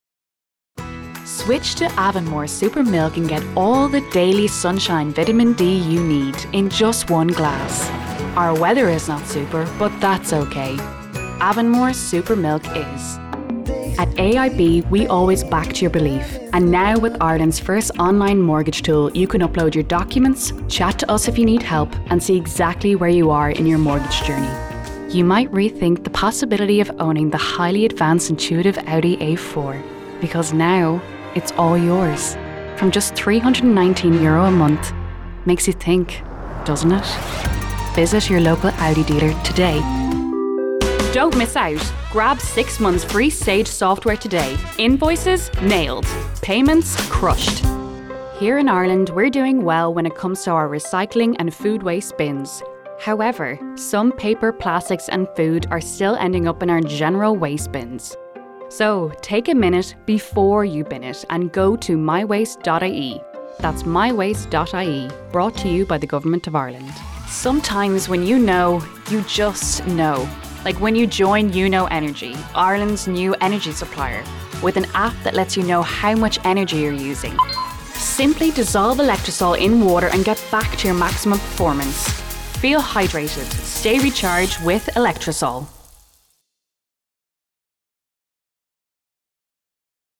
Female
Voice Qualities
20s/30s, 30s/40s
Irish Dublin Neutral, Irish Neutral